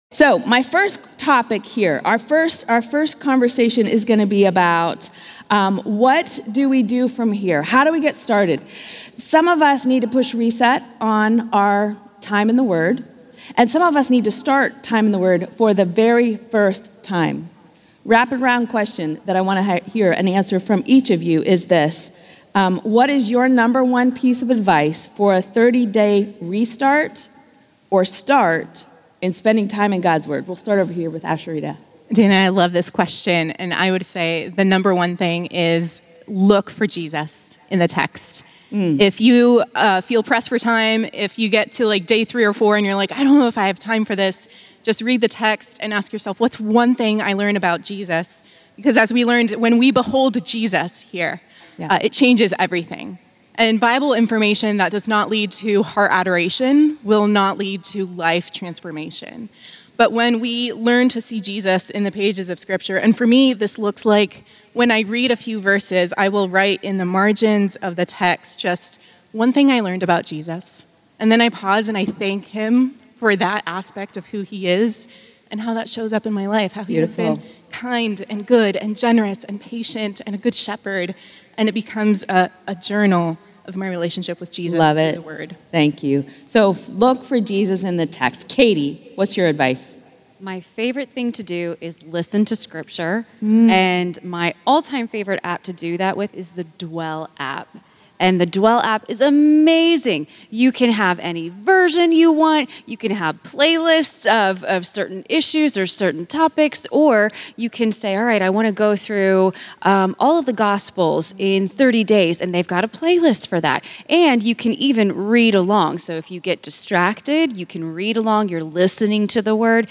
No matter your season, the Word is for you. Hear from a panel of women doing ministry in various life stages and be encouraged to make Scripture your sustenance in every season.